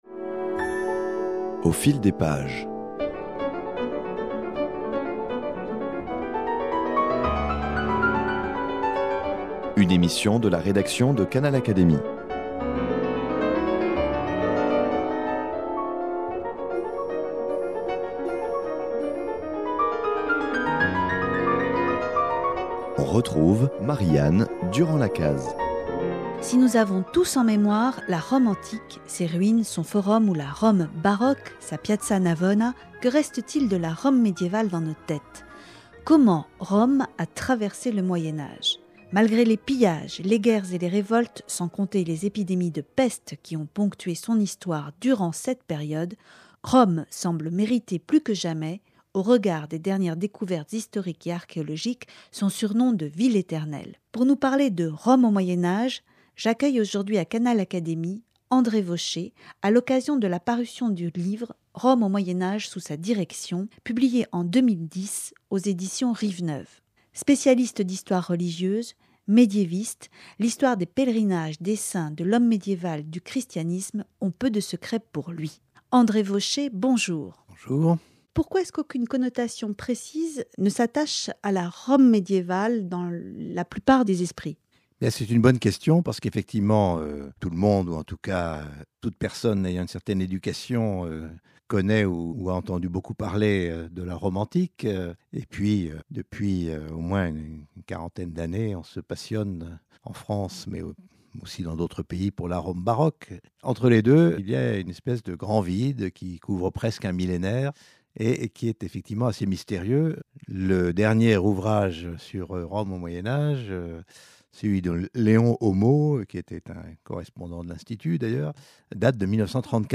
Réponse avec l’historien André Vauchez, membre de l'Académie des inscriptions et belles-lettres.